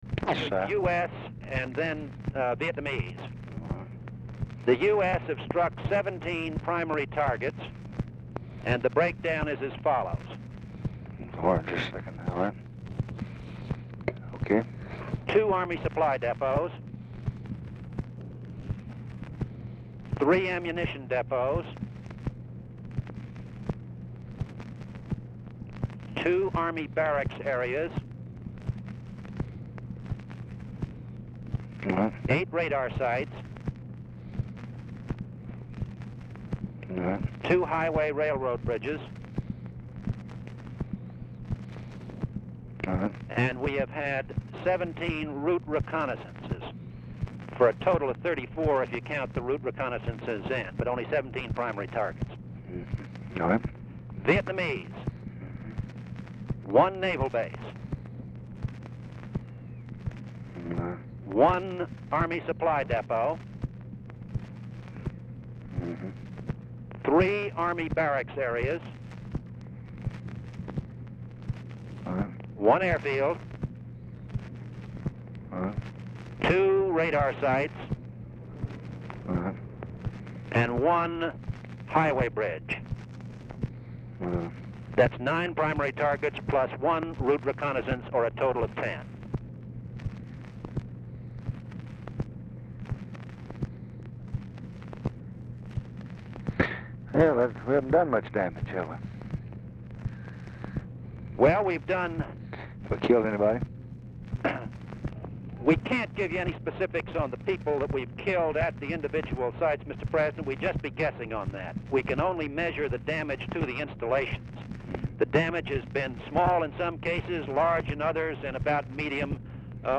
Telephone conversation # 7320, sound recording, LBJ and CYRUS VANCE, 4/6/1965, 3:45PM | Discover LBJ
Format Dictation belt
Location Of Speaker 1 Mansion, White House, Washington, DC